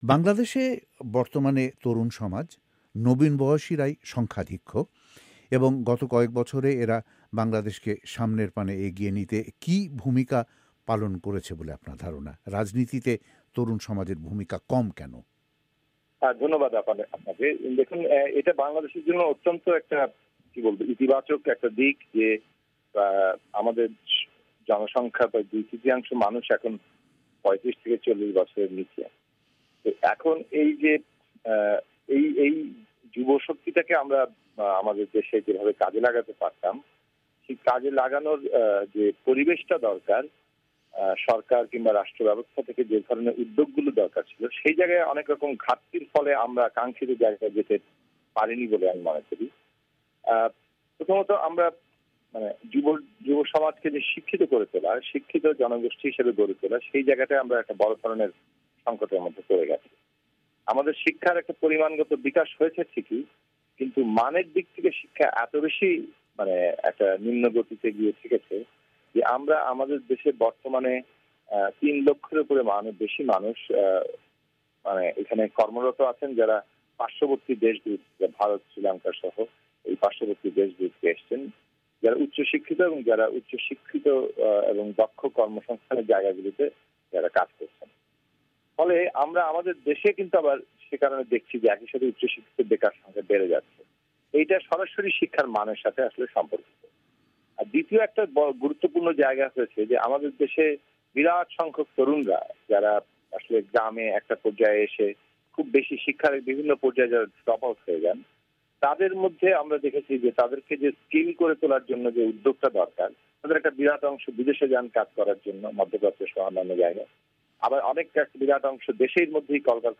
তাঁর সঙ্গে টেলিফোনে কথা বলেন ভয়েস অফ এ্যামেরিকার ওয়াশিংটন স্টুডিও থেকে